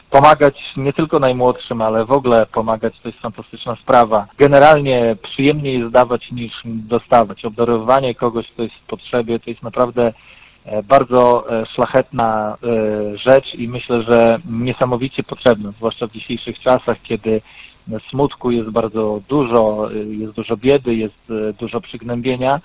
Byliśmy też w ełckim Miejskim Ośrodku Sportu I Rekreacji.